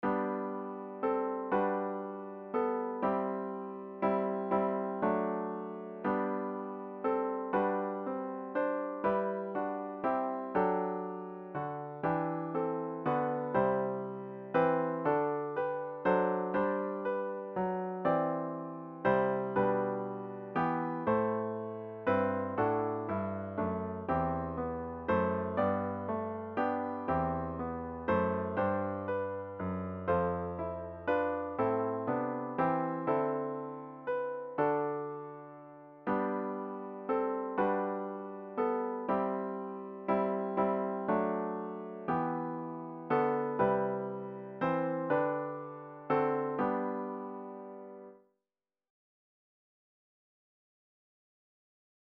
The hymn should be performed at a decisive♩= ca. 120.